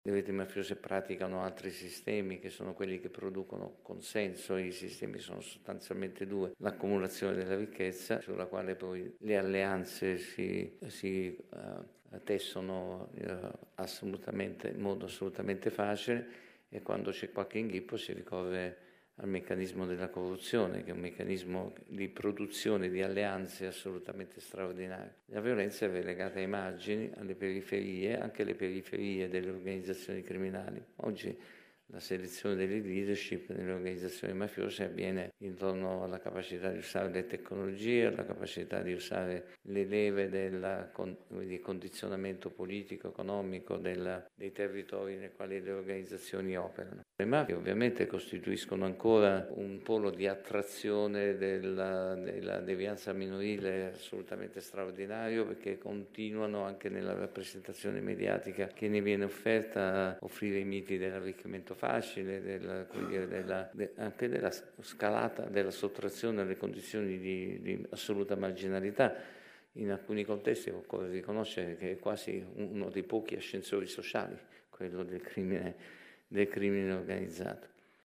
Nel corso del convegno è intervenuto il Procuratore nazionale antimafia Giovanni Melillo, che ha spiegato come le giovani generazioni siano ancora attratte da atteggiamenti criminali.